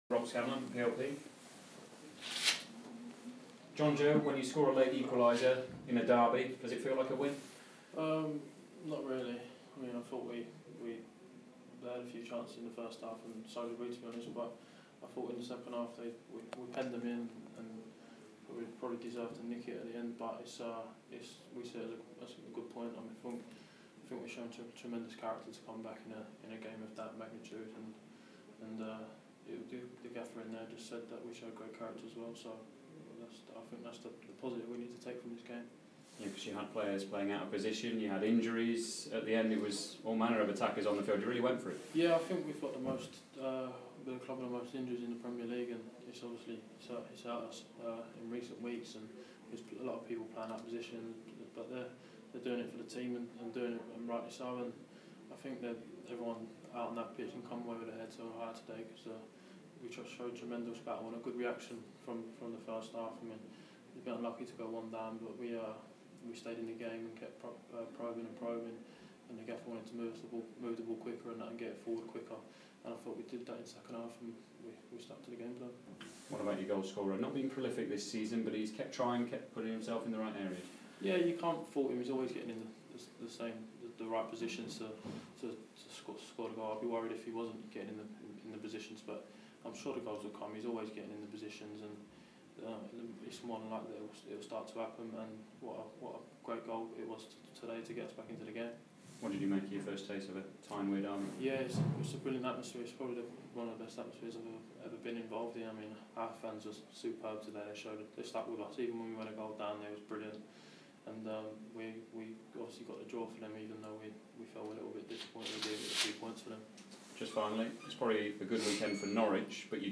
Post-match: Jonjo Shelvey